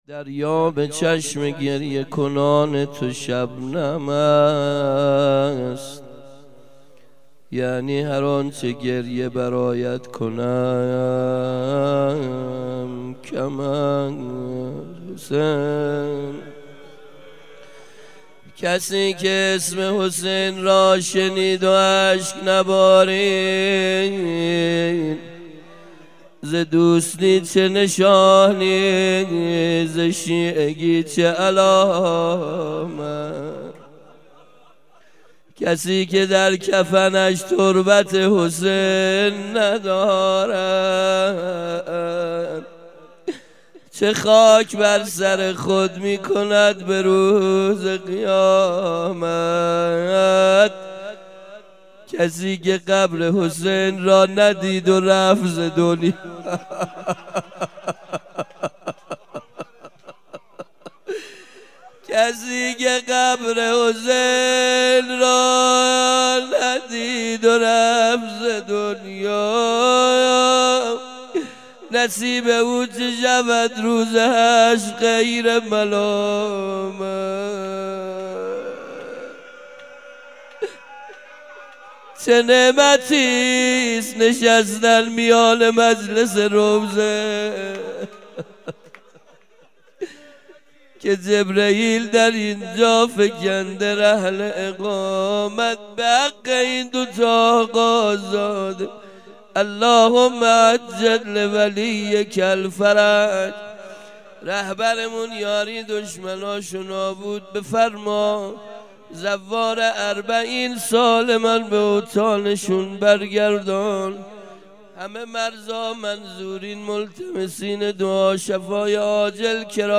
1. حسینیه